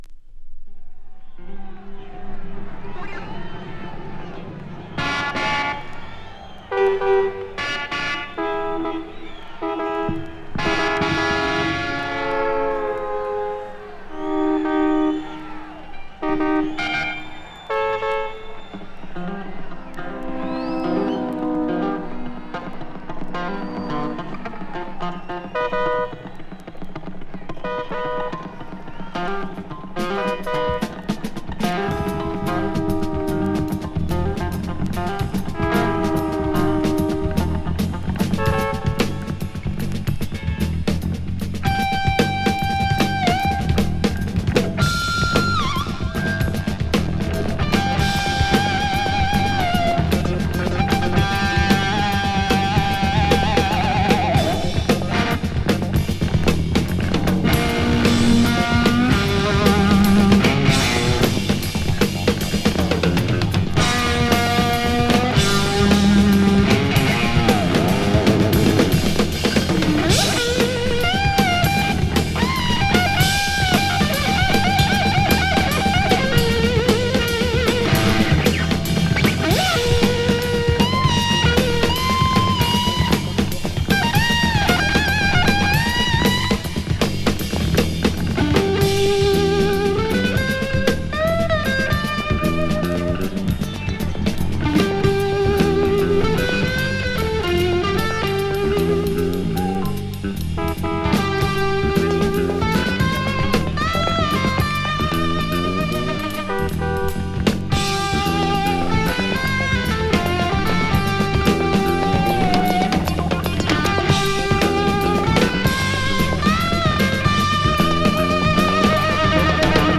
> PSYCHEDELIC/PROGRESSIVE/JAZZ ROCK